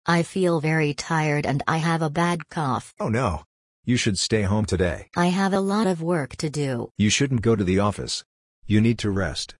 💬 Conversation Practice
Read/listen to the conversation between two friends: